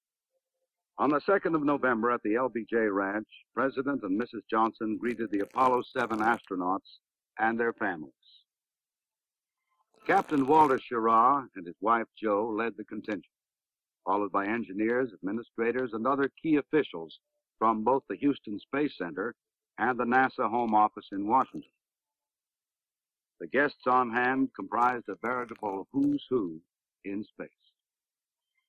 I have 6 minutes of audio I extracted from a 1968 video clip so I could try to clean it up.
Throughout the clip you hear periodic noise. I’ve tried noise removal, but it’s hard to find a segment with only the buzz; it happens only when someone is speaking.
It sounds exactly like multi-path distortion from an FM radio.
As the defects are mostly not simultaneous on left and right channels, then producing only what is common to both channels (with Kn0ckout) has less defects …